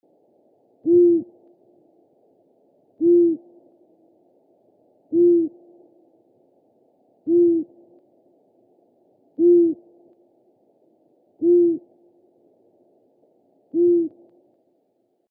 Сова болотная